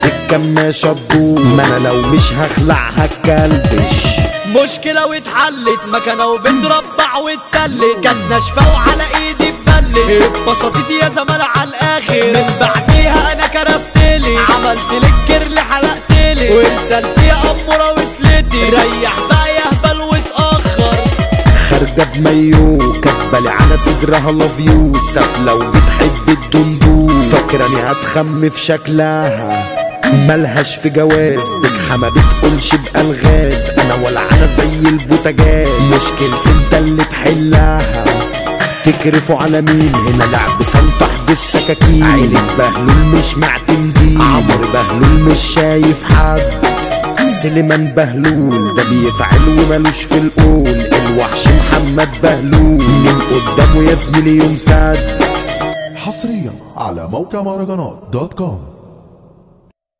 مهرجان